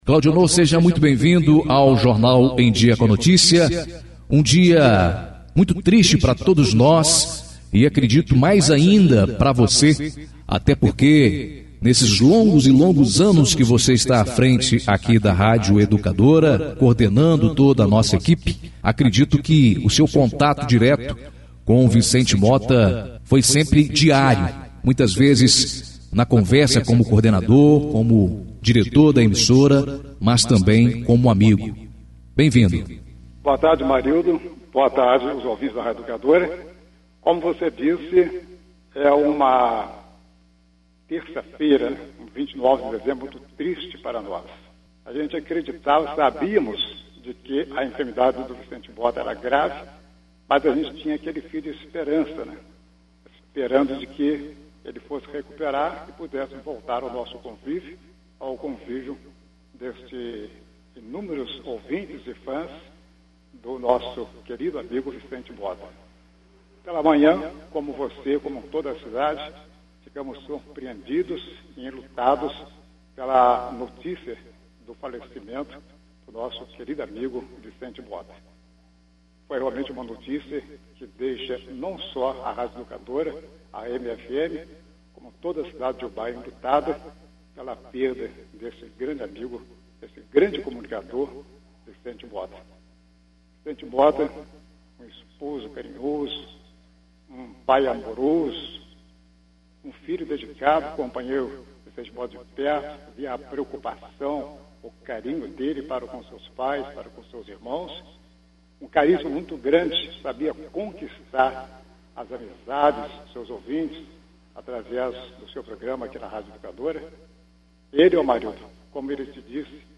ao vivo nesta terça-feira, no jornal em dia com à notícia.